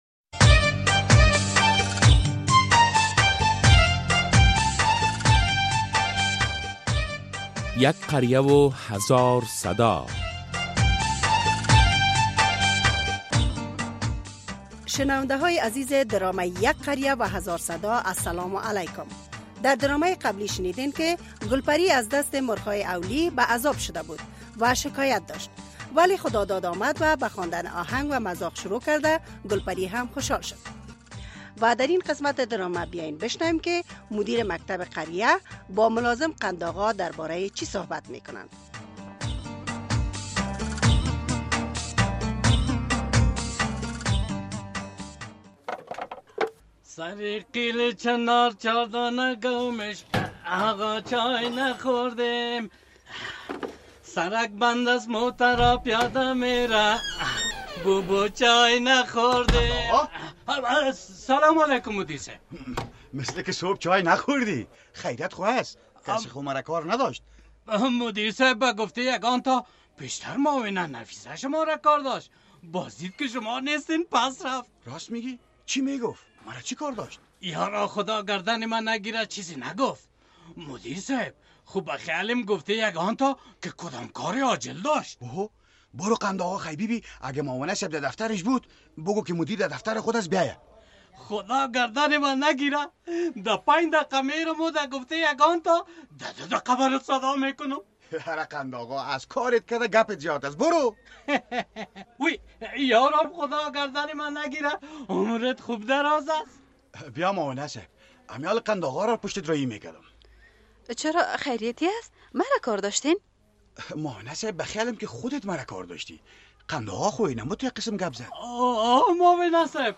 درامه یک قریه هزار صدا قسمت ۲۰۵